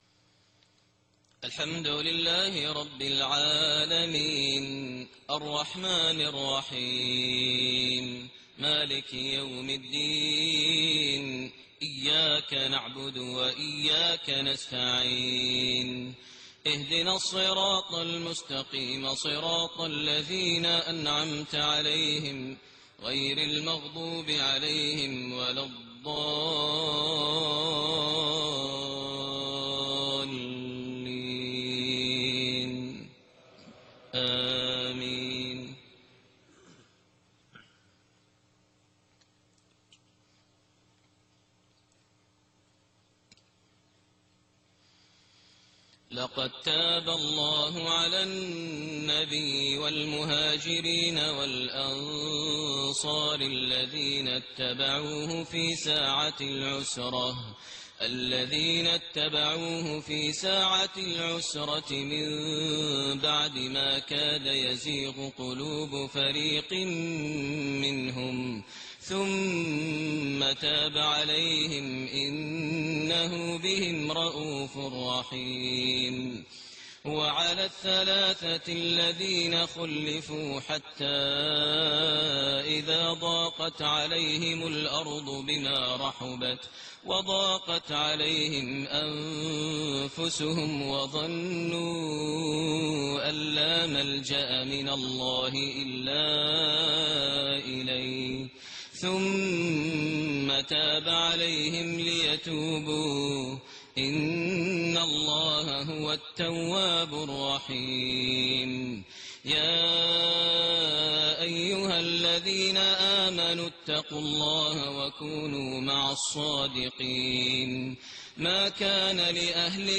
صلاة الفجر5-5-1429 من سورة التوبة 117-129 > 1429 هـ > الفروض - تلاوات ماهر المعيقلي